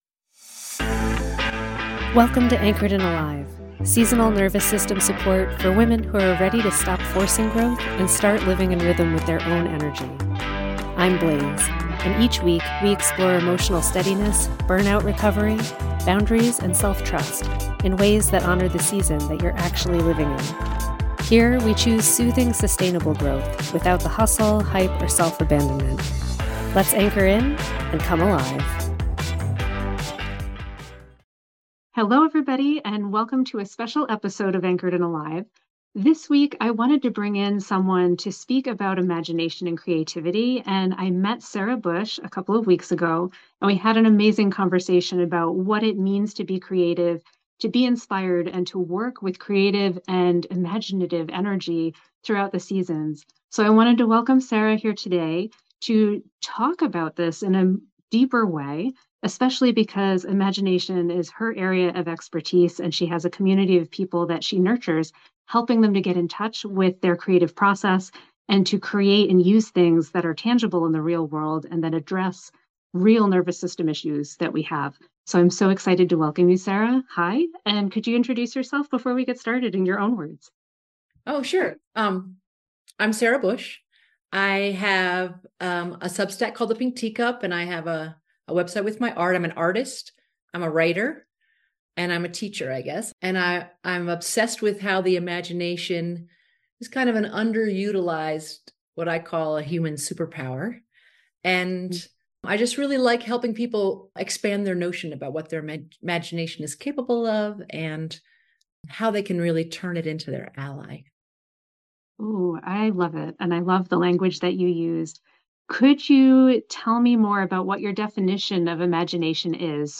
This week’s episode is a special bonus conversation placed between Episodes 23 and 24, where we’re exploring how to work with rising spring energy without burning out.